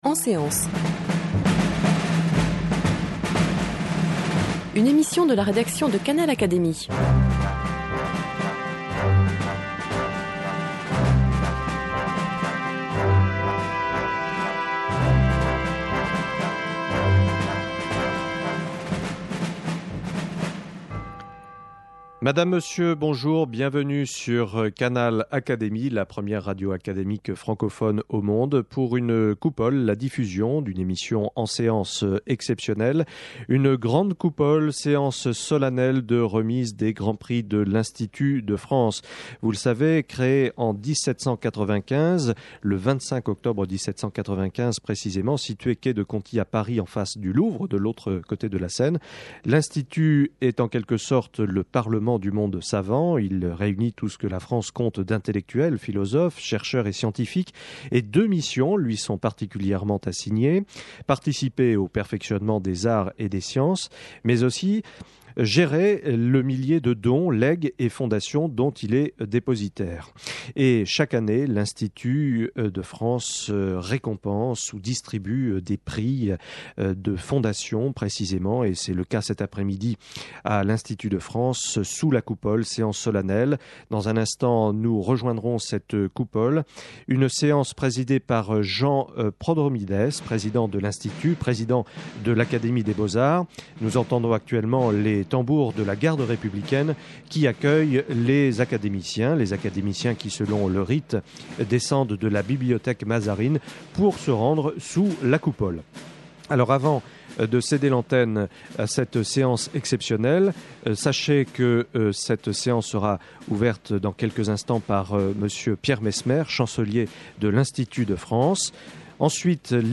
Retransmission de la séance solennelle de remise des Grands Prix de l’Institut de France sous la Coupole de l’Institut le mercredi 15 juin 2005.